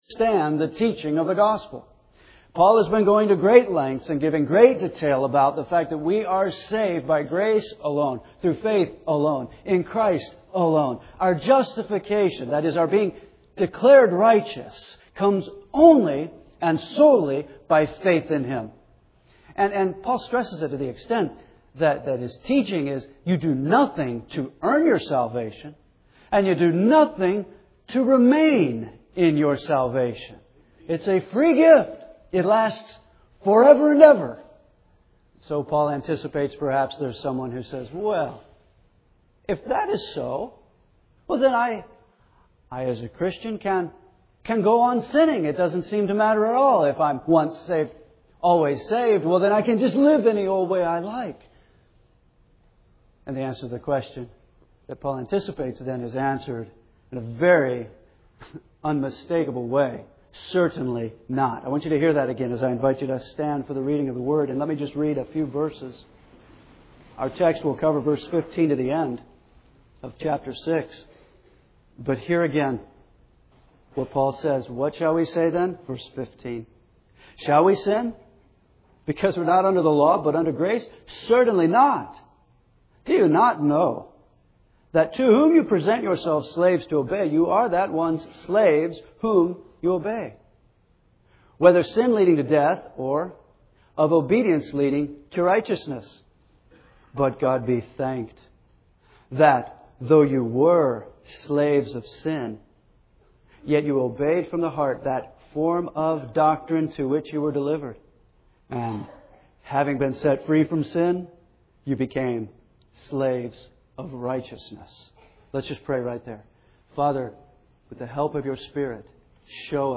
We are continuing our verse-by-verse expository preaching series through the book of Romans, a book largely about the Gospel.